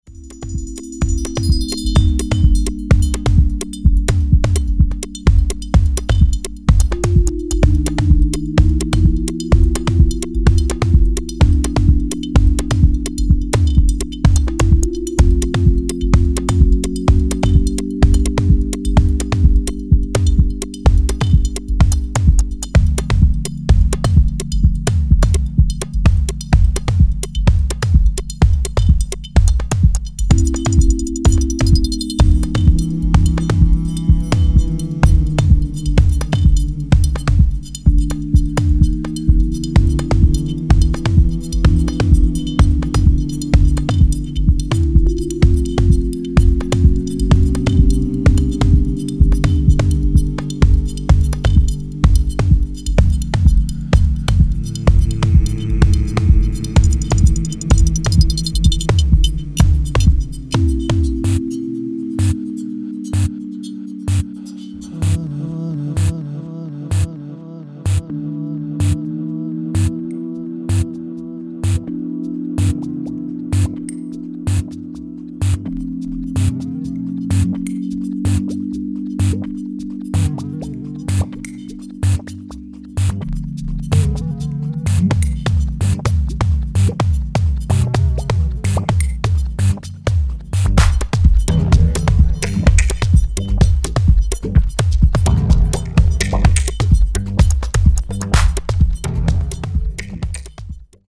妖艶に蠢くアヴァンギャルドな音像、あくまでもファンキーでしなやかなハウスビート。